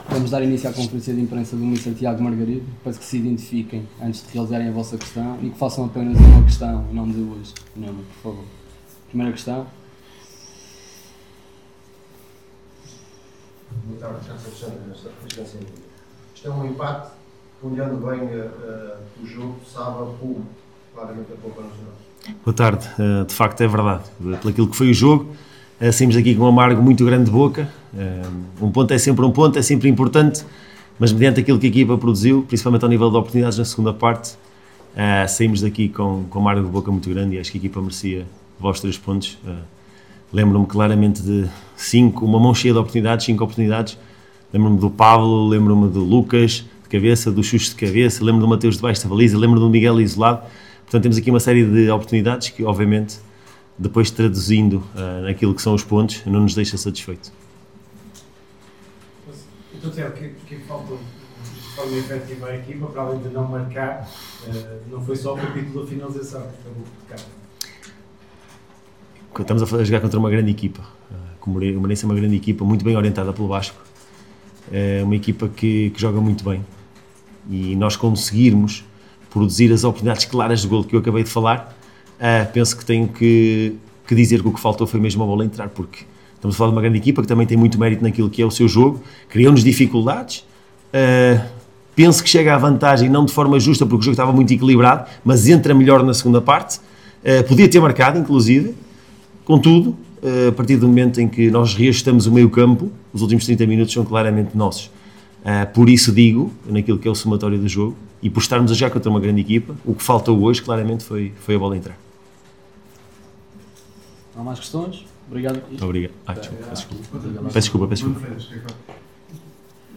Na conferência de imprensa realizada no final do encontro para a 25.ª jornada da Liga Portugal Betclic